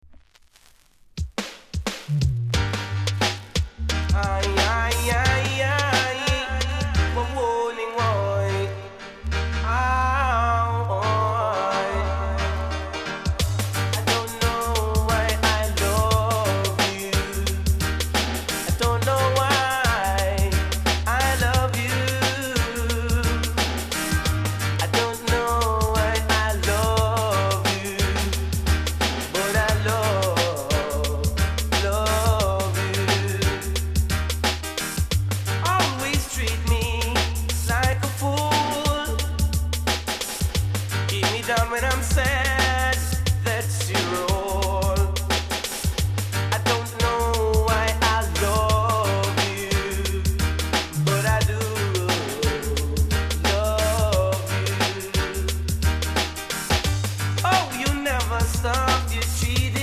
B VERSION ������ ��TEMPO